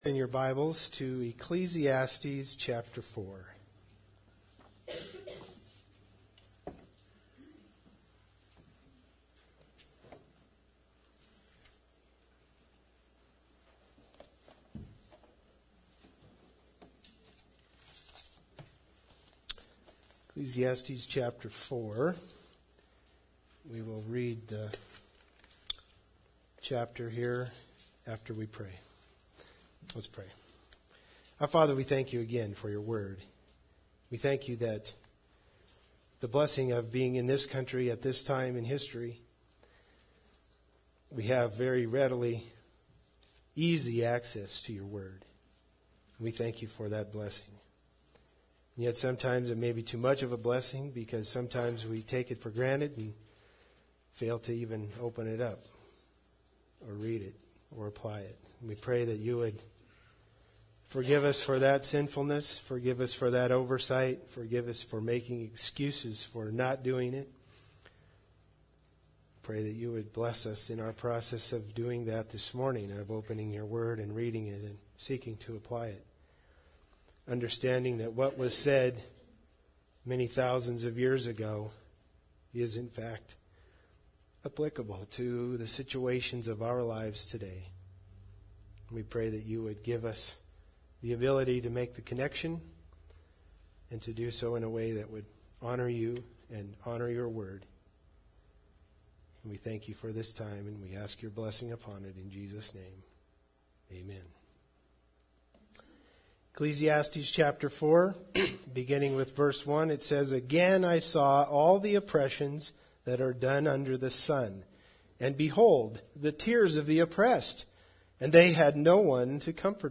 Passage: Ecclesiastes 4:7-12 Service Type: Sunday Service